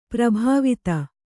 ♪ prabhāvita